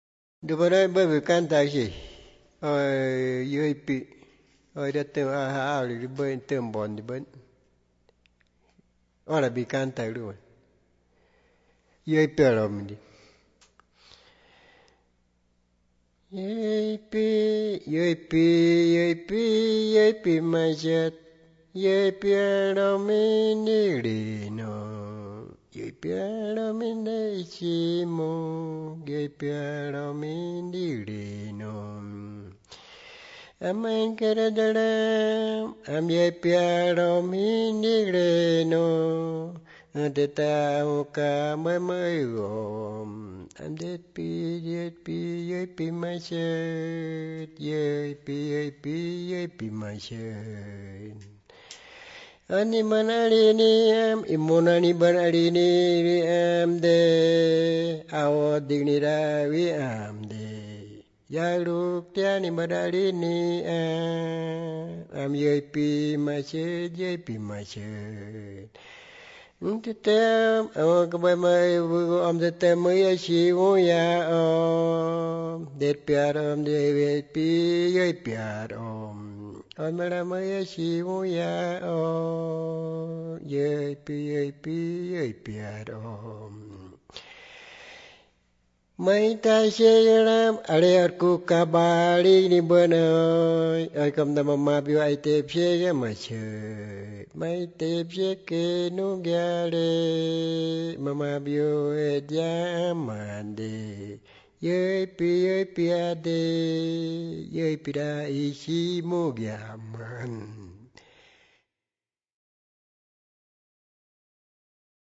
Piedra Alta, Medio Inírida, Guainía (Colombia)
Dice el cantor "yo pez negro , distinto de usted, usted no podrá conmigo…¿será que usted me traerá mi comida? Ahí en lo hondo en mi casa estoy, usted me pone trampa, pero yo le robaré su anzuelo". La grabación, transcripción y traducción de la canción se hizo entre marzo y abril de 2001 en Piedra Alta; una posterior grabación en estudio se realizó en Bogotá en 2004
Canciones Wãnsöjöt